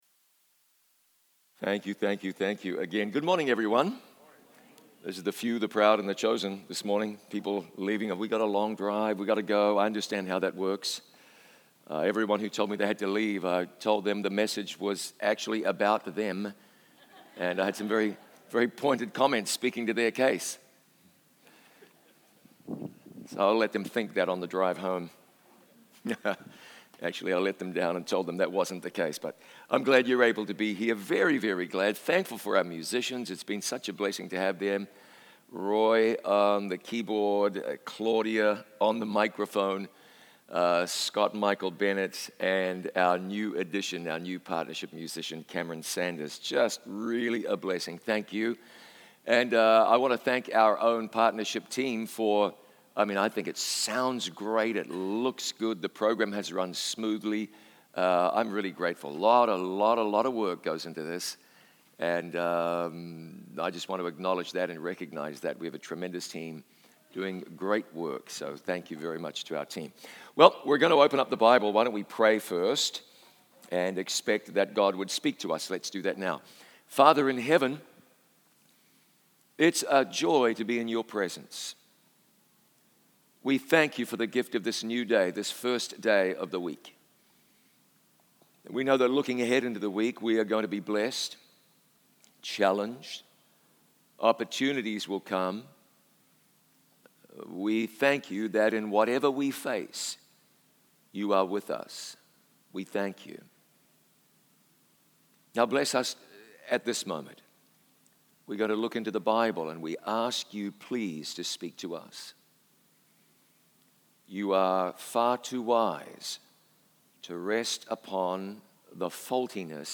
Sunday-Sermon-MP3.mp3